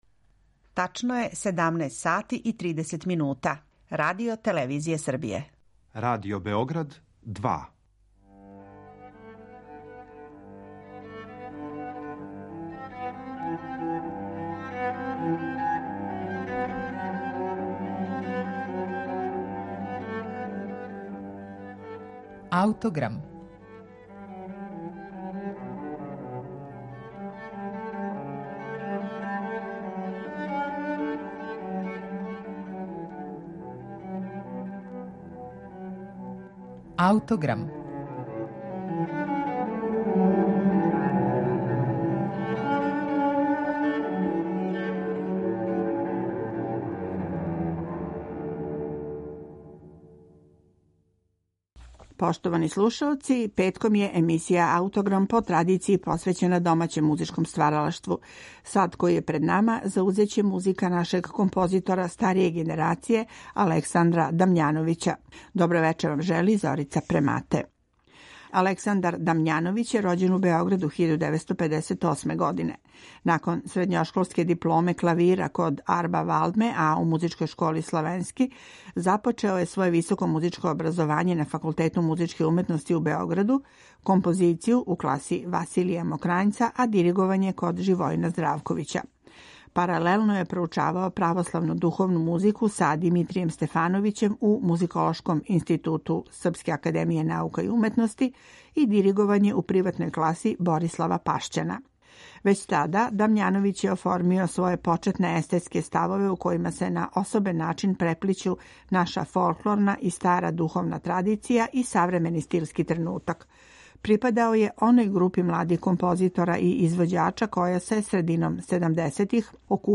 Са тог концерта потиче и наш снимак.